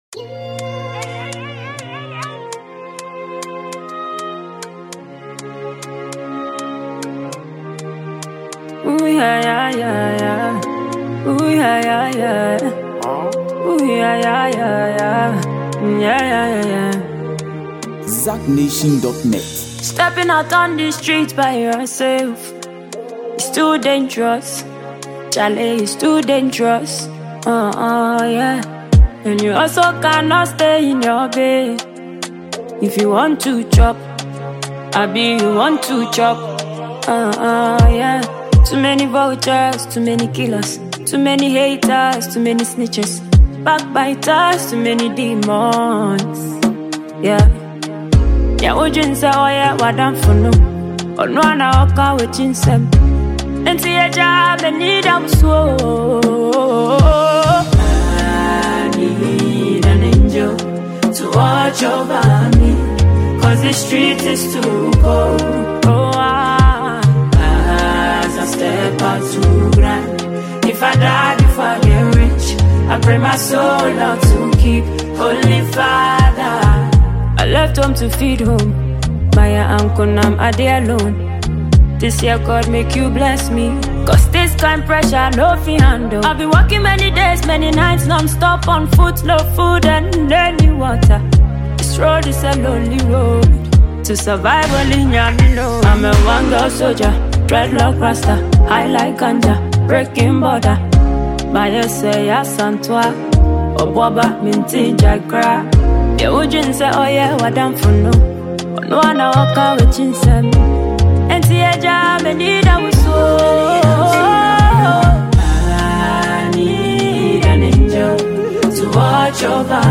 The production value is excellent.